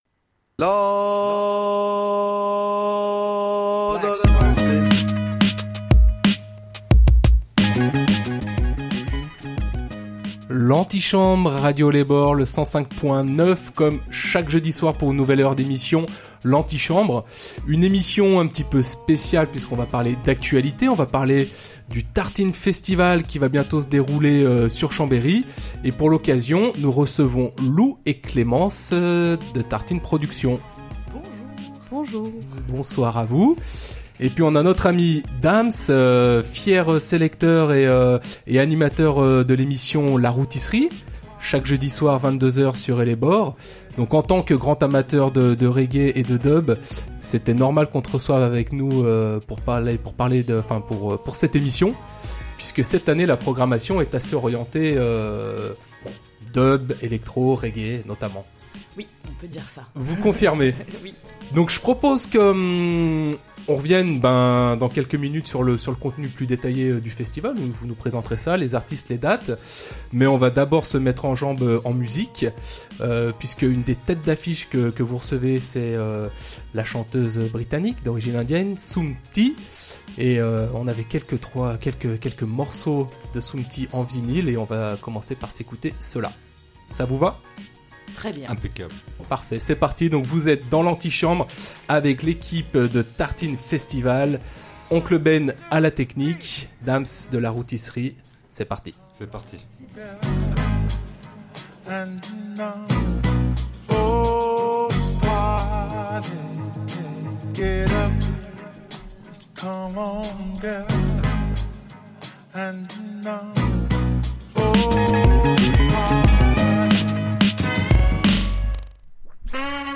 accueillait la semaine dernière les organisateurs du Festival: